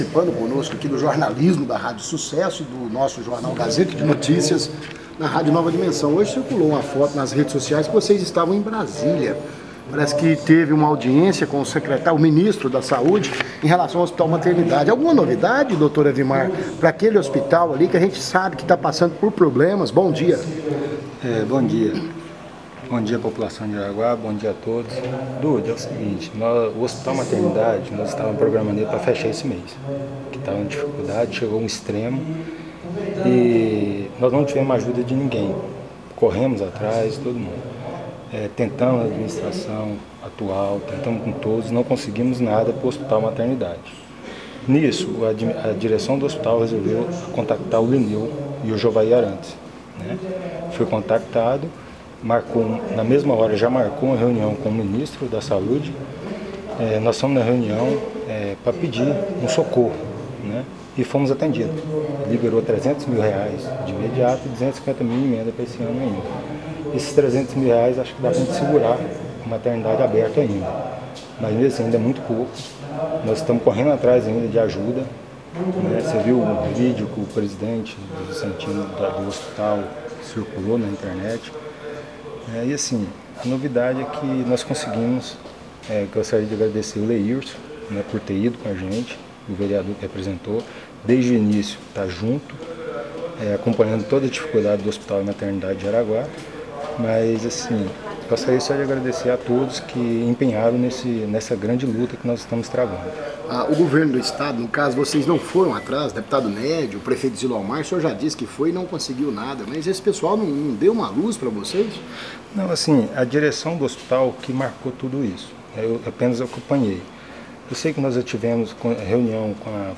Em entrevista à Rádio Nova Dimensão e ao Jaraguá Notícia